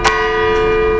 data/baby_cry_detection/no_crying/1-21421-A_part2.wav · A-POR-LOS-8000/CHATBOT at b9984feea44f565fb163f3b98edfc0960dc27dba